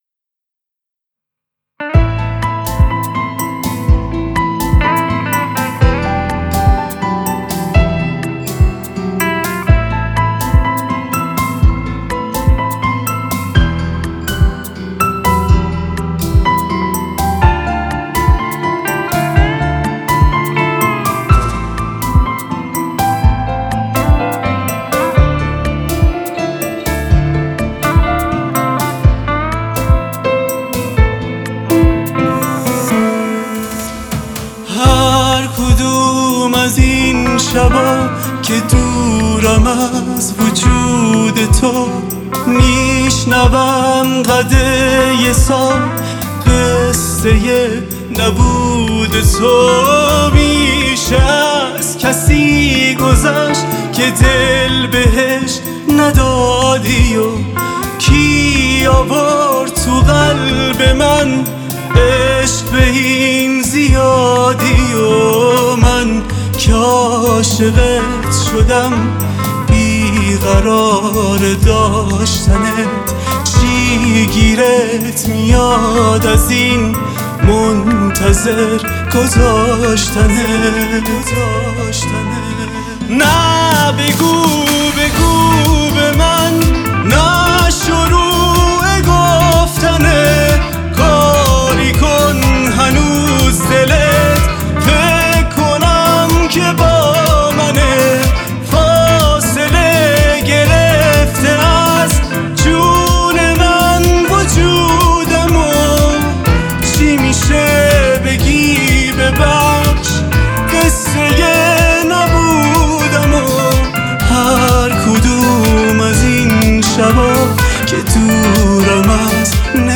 آهنگ ایرانی